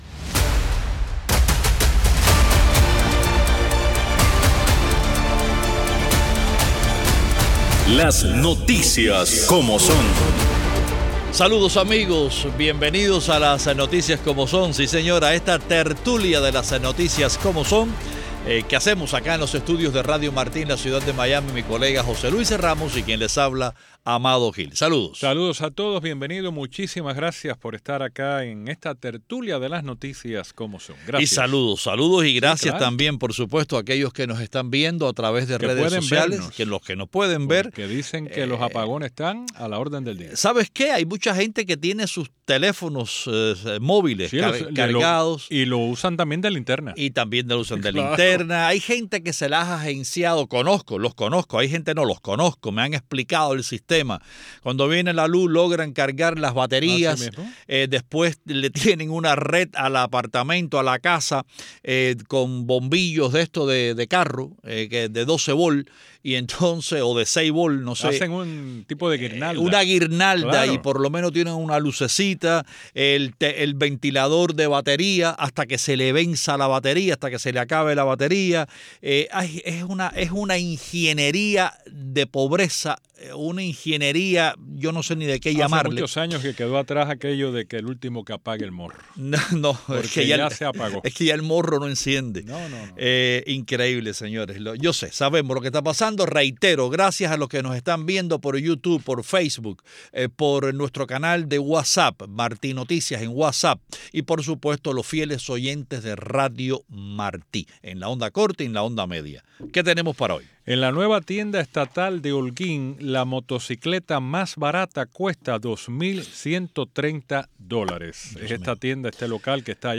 Tertulia